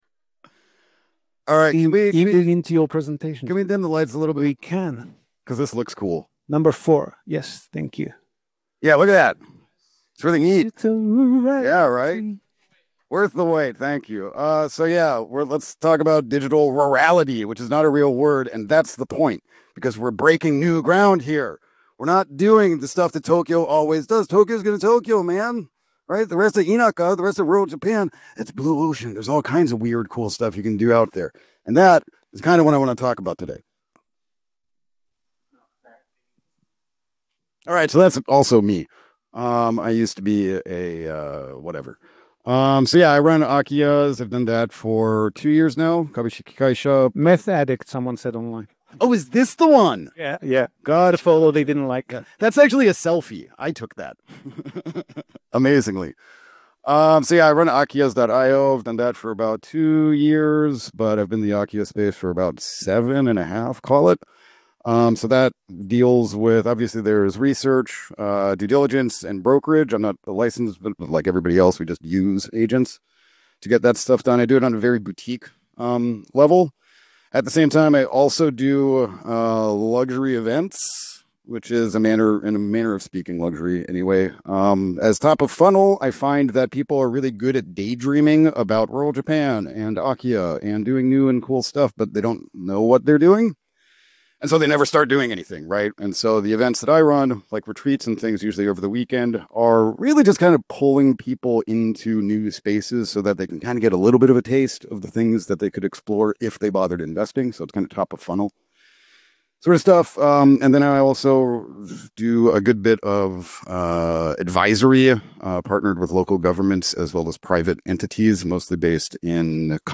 main room presentation at the JRE Summit, spring 2025